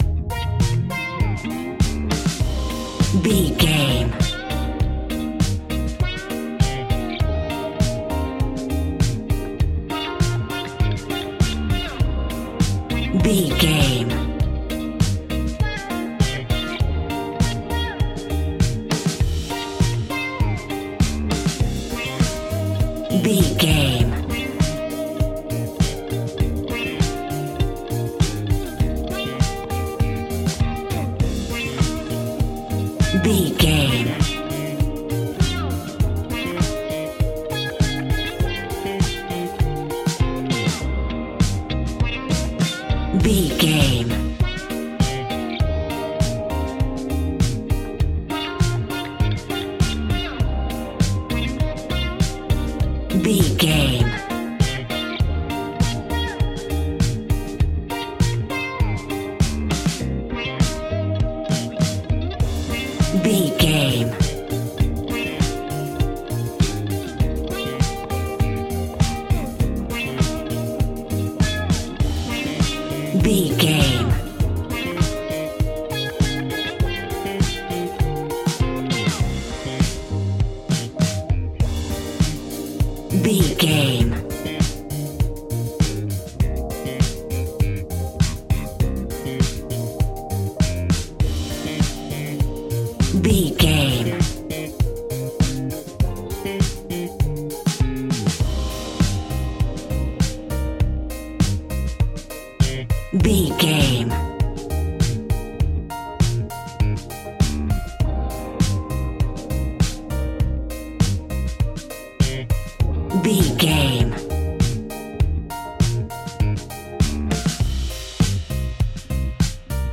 Ionian/Major
house
electro dance
synths
techno
trance